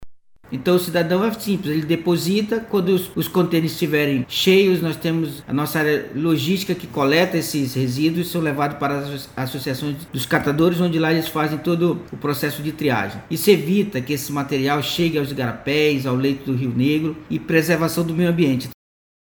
O secretário da pasta, Altervi Moreira, explica como funciona.
Sonora-1-Altervi-Moreira-–-secretario-Semulsp.mp3